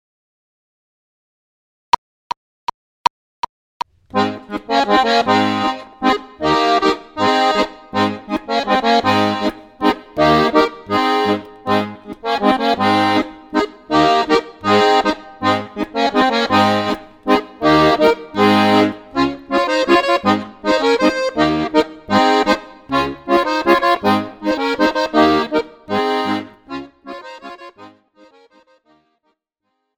Besetzung: Tuba